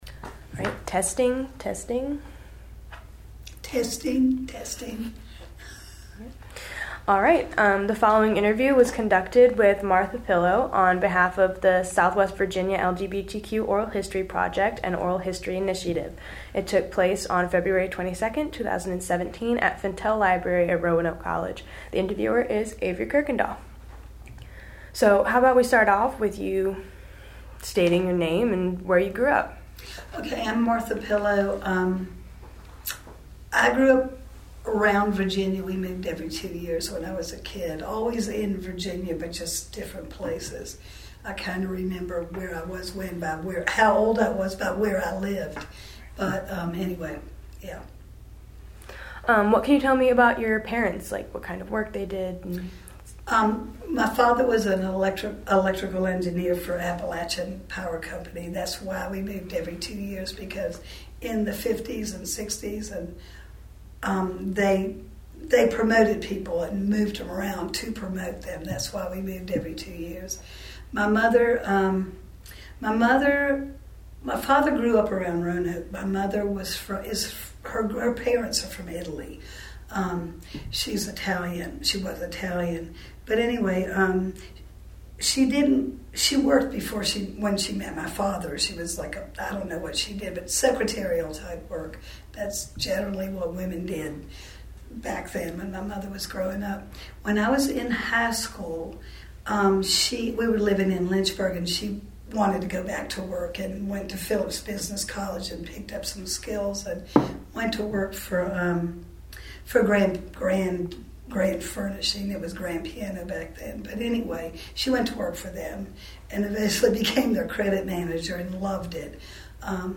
Oral History Interview
Location: Fintel Library, Roanoke College